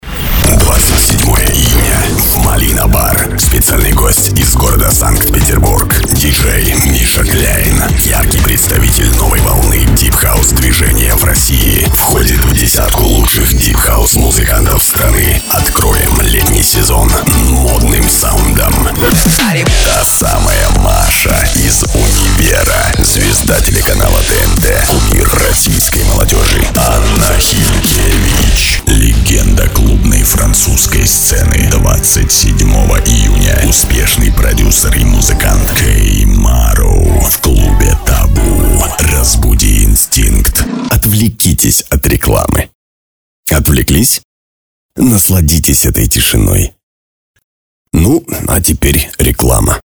Тракт: Rft Veb Mikrofontechnik Gefell – M-Audio FireWire 410 – Pop Filter Rode